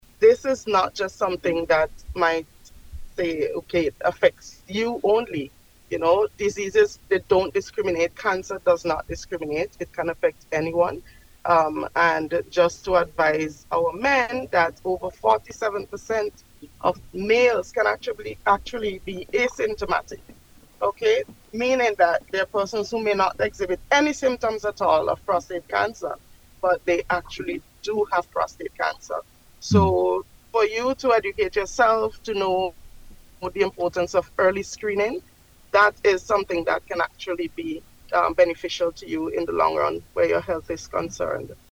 Speaking on NBC Radio yesterday as part of awareness activities at the Milton Cato Memorial Hospital